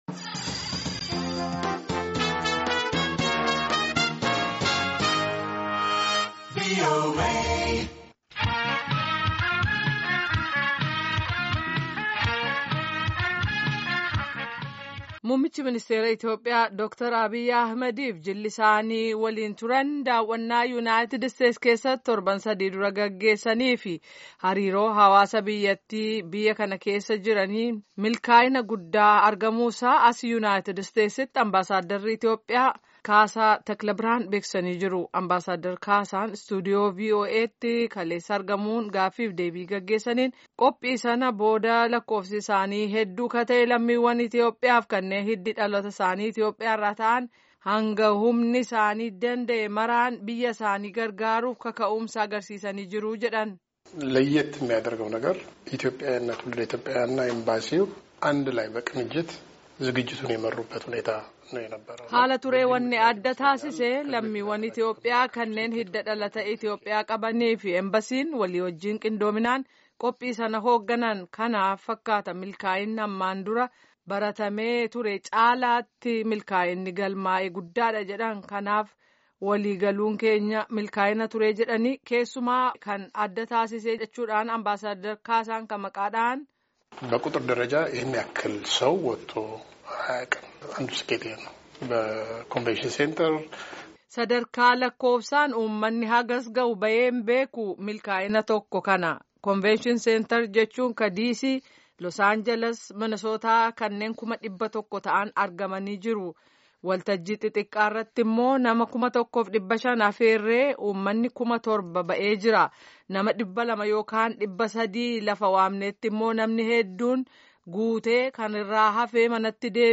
Amerikaatti Ambaasaaddara Itiyoophiyaa kan ta’an Kaasaa Takla Birhaan waajiira raadiyoo sagalee Amerikaatti argamuun akka ibsanti lammiwwan Itiyoophiyaas ta’e kanneen hiddi dhalata isaanii Itiyoophiyaa irraa ta’e biyya isaanii gargaaruuf kaka’uumsa guddaa akka qaban ibsan.